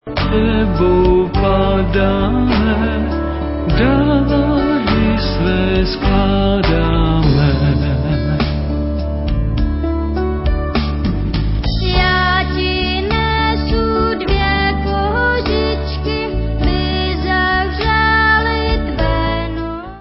album vánočních písní
sledovat novinky v kategorii Pop